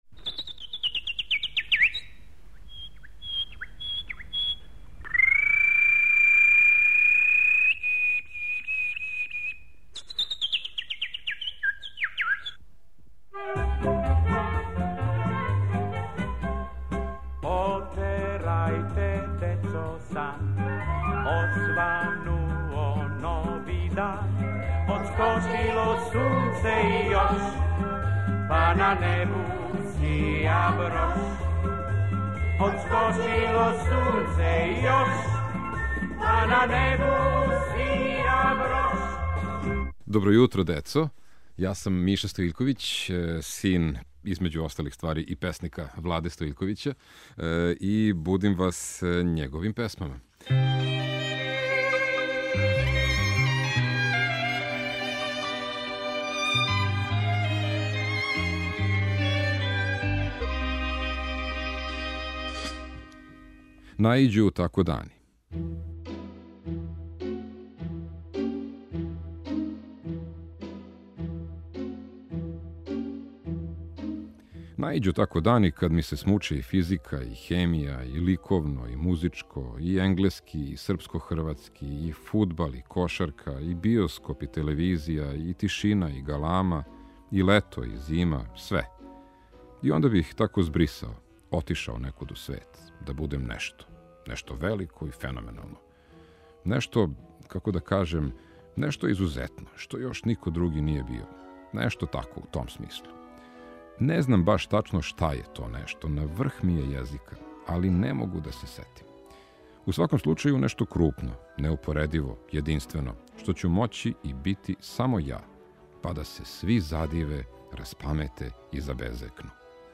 у пратњи Дечије драмске групе Радио Београда и деце глумаца из О.Ш. Вељко Рамадановић